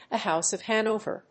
アクセントthe Hóuse of Hánover